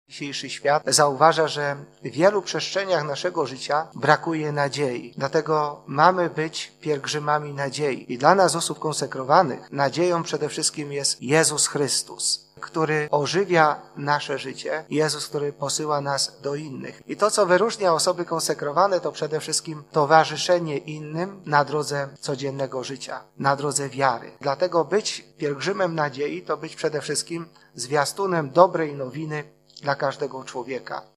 Osoby konsekrowane były, są i będą pielgrzymami nadziei– powiedział biskup Jacek Kiciński, przewodniczący Komisji Konferencji Episkopatu Polski ds. Instytutów Życia Konsekrowanego i Stowarzyszeń Życia Apostolskiego podczas konferencji prasowej zapowiadającej Światowy Dzień Życia Konsekrowanego.
Osoby konsekrowane są tymi, którzy niosą miłość Boga do każdego człowieka -podkreślał biskup Kiciński: